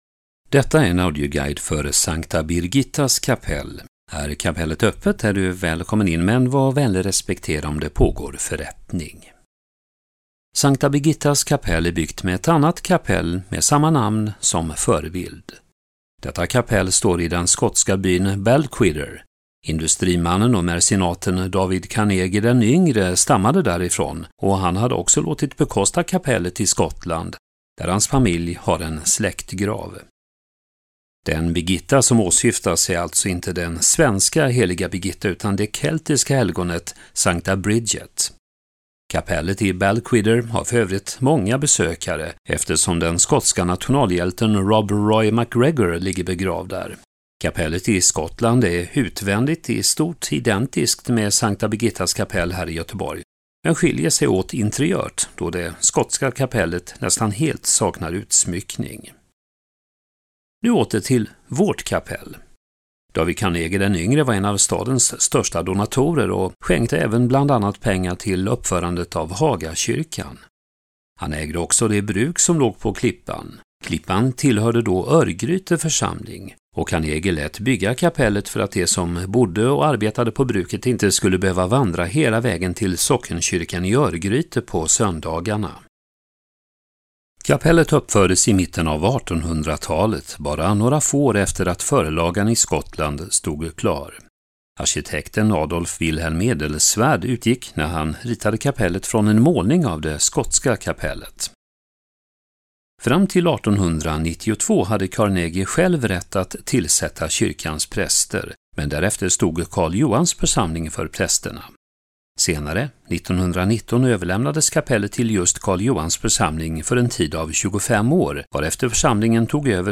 Lysssna på en audioguide om S:ta Birgittas kapell